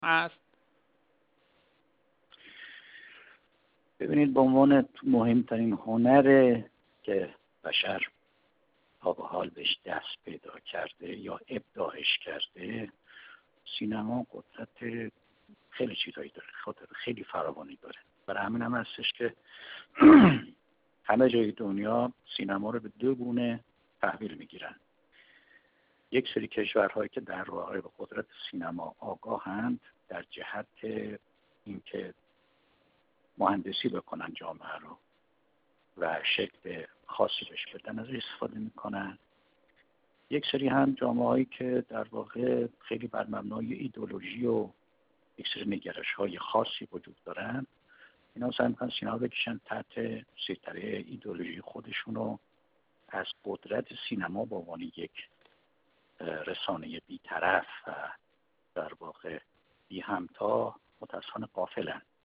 در گفت‌وگو با ایکنا: